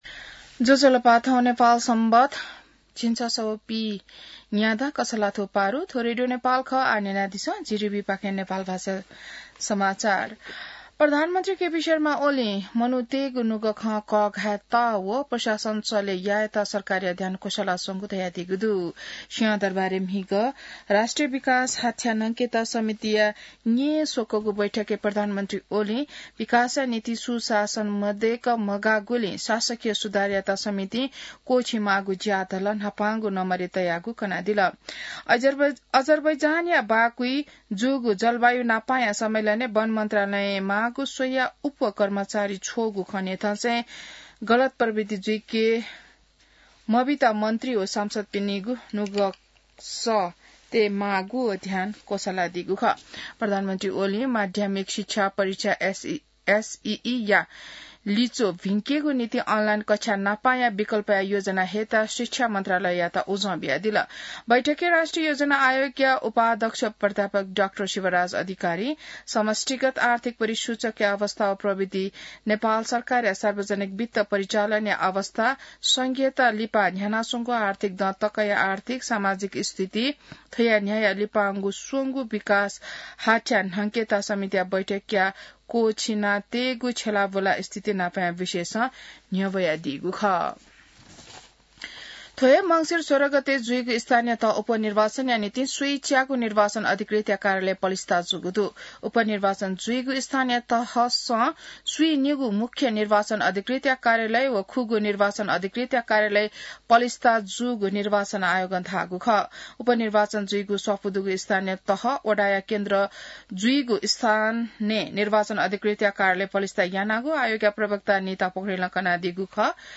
नेपाल भाषामा समाचार : २ मंसिर , २०८१